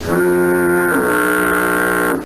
[SOUND] SpongeBob Disgustment.ogg